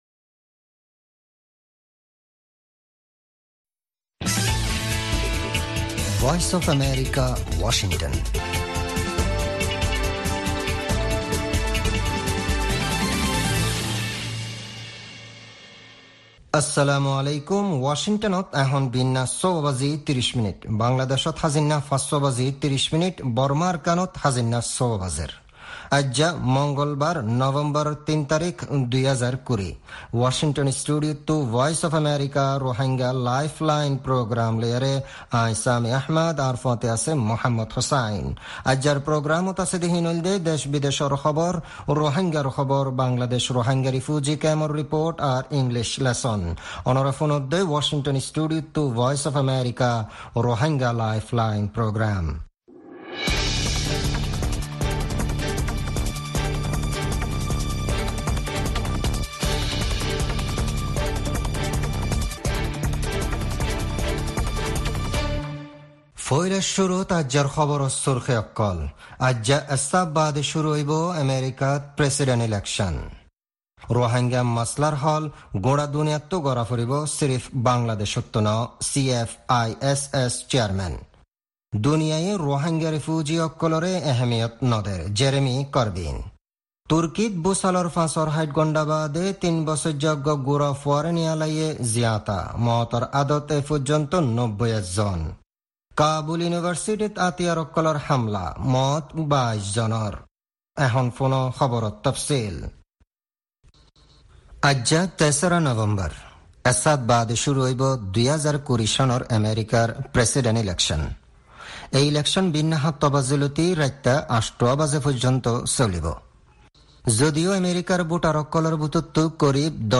Rohingya Broadcast
Embed Rohingya Broadcast Embed The code has been copied to your clipboard.